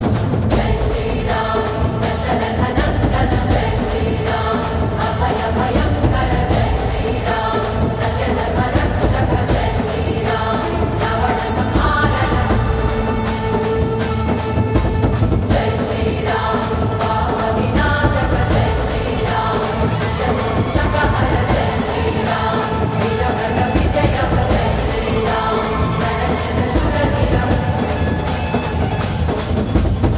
Download devotional MP3 ringtone with loud, clear sound.
• Pure devotional (Bhakti) vibe